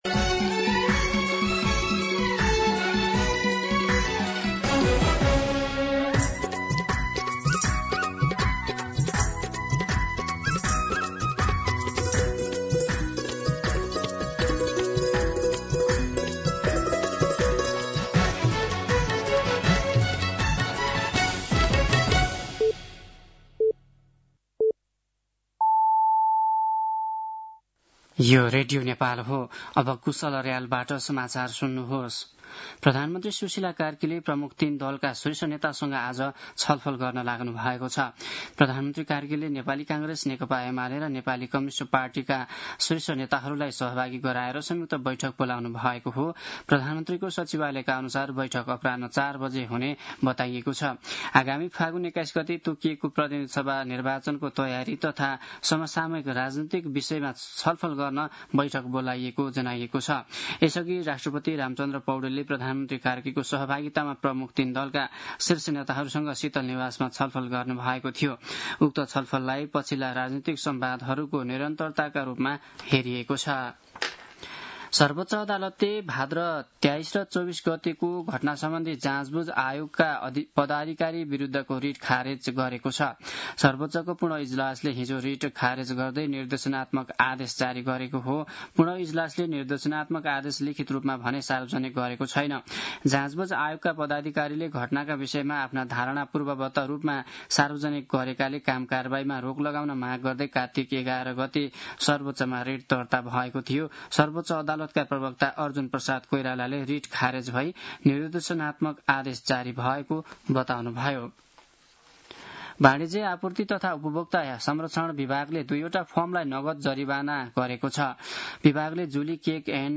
मध्यान्ह १२ बजेको नेपाली समाचार : १२ पुष , २०८२
12-pm-Nepali-News-4.mp3